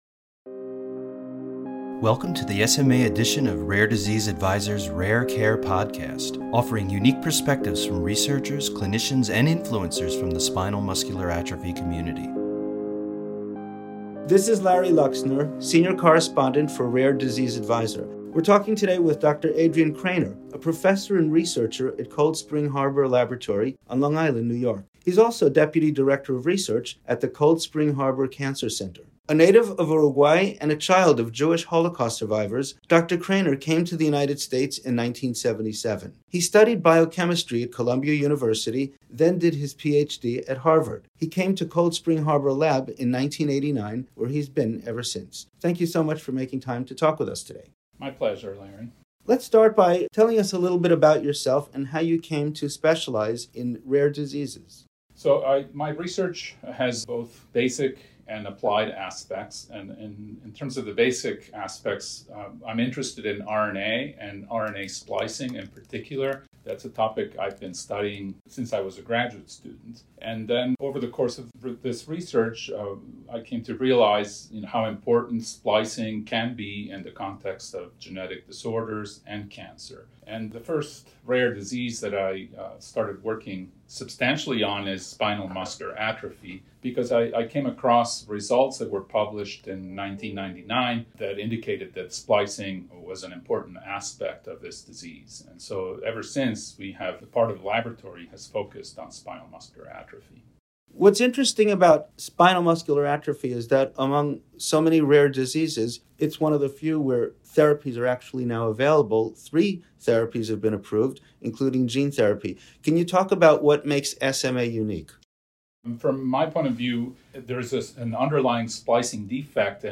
16: An Interview